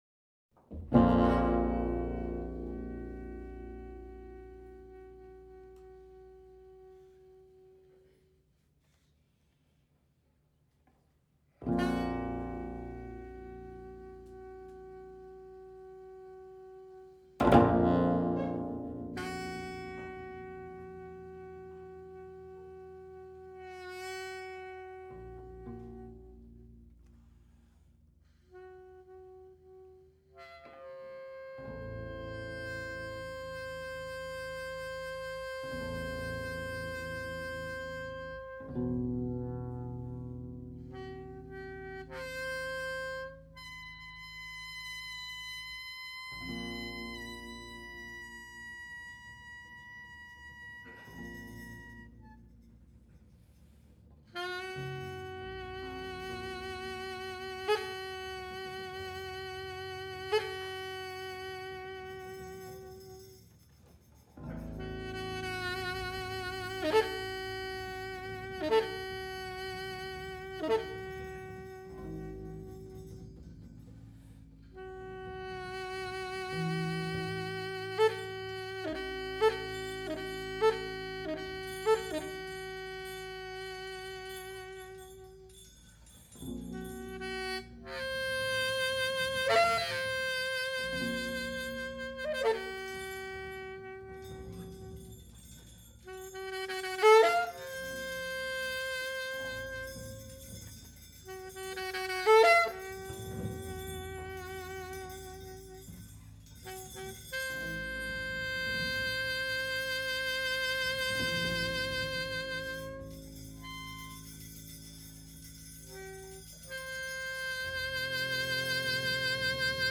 Alto Saxophone, Soprano Saxophone
Double Bass
Recorded live